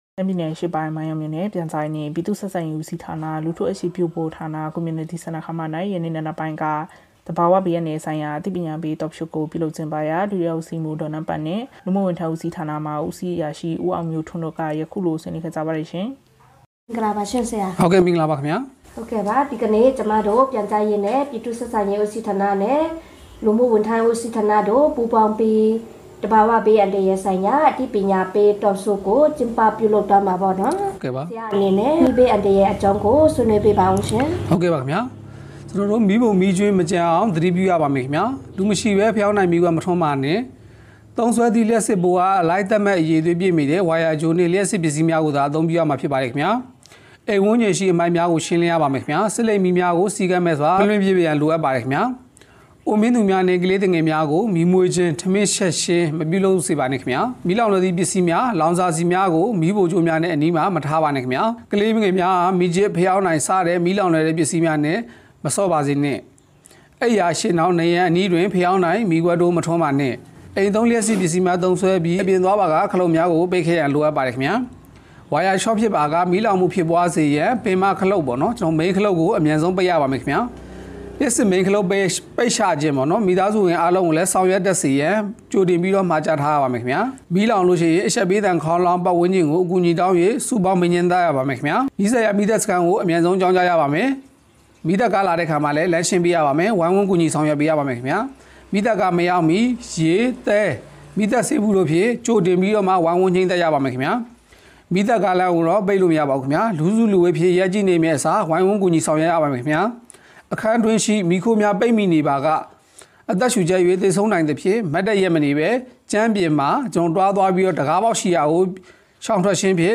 မိုင်းယောင်းမြို့တွင် သဘာဝဘေးအန္တရာယ်ကြိုတင်ကာကွယ်ရေးဆိုင်ရာ အသိပညာပေး Talk Show ကျင်းပ မိုင်းယောင်း စက်တင်ဘာ ၁၀